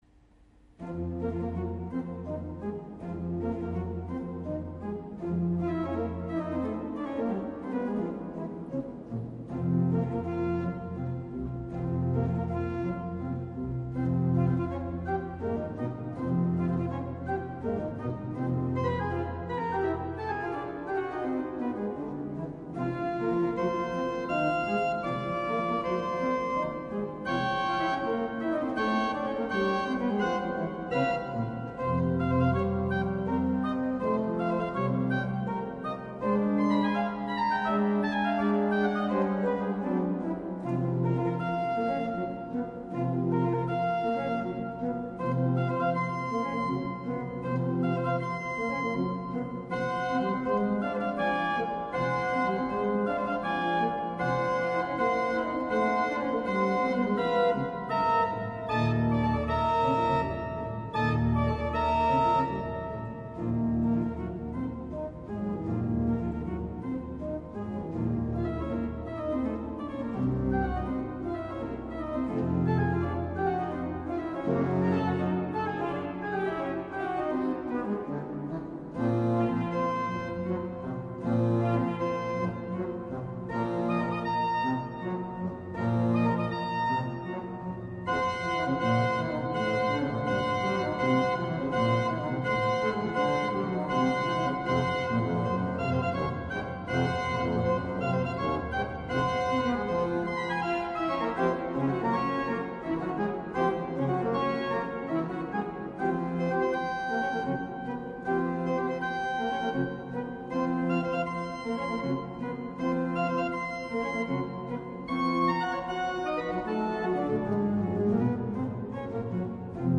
orgue de Chœur de la Cathédrale de Rouen